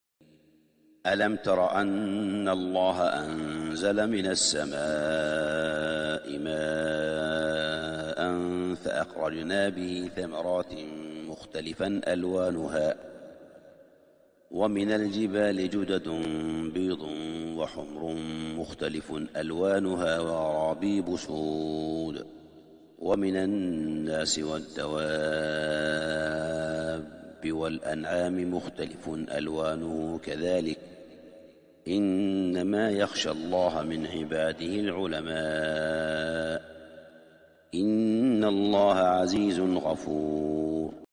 تسجيل إستديو سورة فاطر 27-28 | Surah Fatir studio recording > " تسجيل إستديو للشيخ صالح بن حميد " > تلاوات و جهود الشيخ صالح بن حميد > المزيد - تلاوات الحرمين